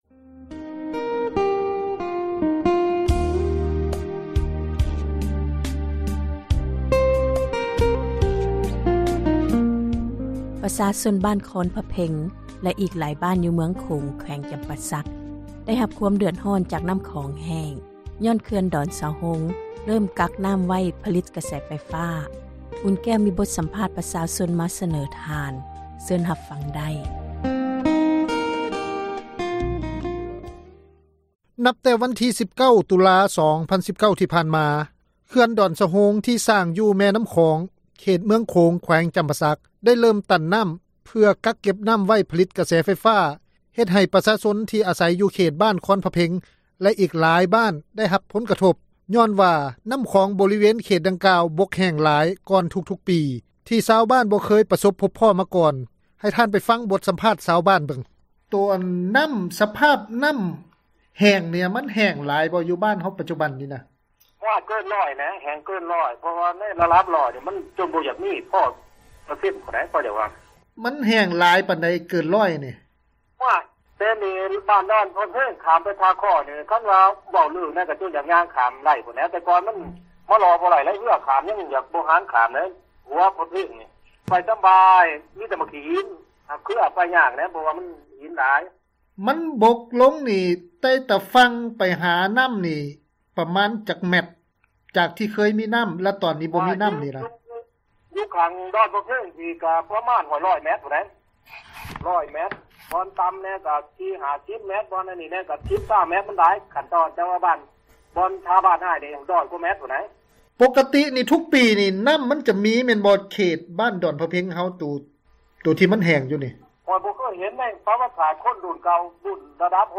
ນັບແຕ່ວັນທີ 19 ຕຸລາ ປີ 2019 ທີ່ ຜ່ານມາ, ເຂື່ອນດອນສະໂຮງ ທີ່ສ້າງຢູ່ແມ່ນ້ຳຂອງ ເຂດ ເມືອງໂຂງ ແຂວງຈຳປາສັກ ໄດ້ເລີ້ມຕັນນ້ຳ ເພືອກັກນ້ຳໄວ້ຜລິດ ກະແສໄຟຟ້າ ເຮັດໃຫ້ປະຊາຊົນທີ່ອາໃສ ຢູ່ເຂດບ້ານຄອນພະເພັງ ແລະ ອີກຫລາຍບ້ານ ໄດ້ຮັບ ຜົນກະທົບ ຍ້ອນວ່າ ນ້ຳຂອງບໍຣິເວນເຂດດັ່ງກ່າວ ບົກແຫ້ງຫລາຍກ່ອນ ທຸກປີ ທີ່ຊາວບ້ານບໍ່ເຄີຍ ປະສົບພົບພໍ້ມາກ່ອນ... ເຊີນທ່ານ ຟັງບົດສຳພາດ ຊາວບ້ານ ລອງເບິ່ງ...